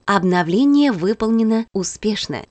Звуки робота-пылесоса
Звук движения робота-пылесоса к зарядной станции